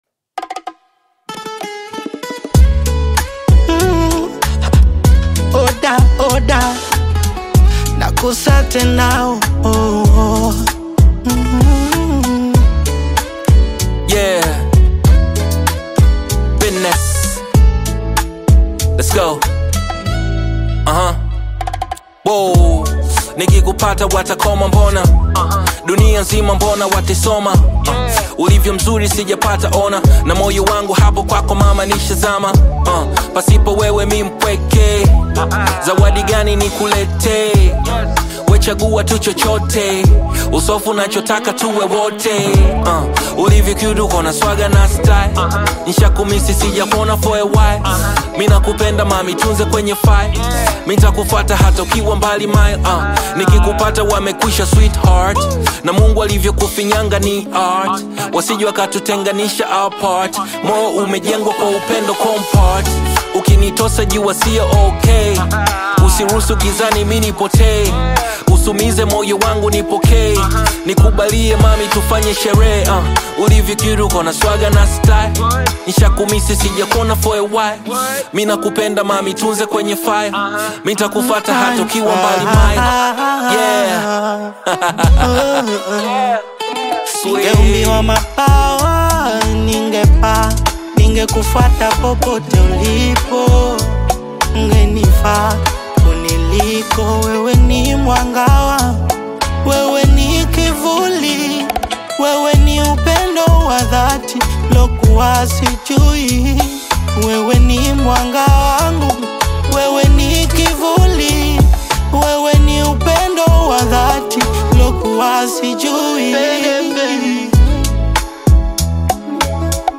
Nigerian singer-songsmith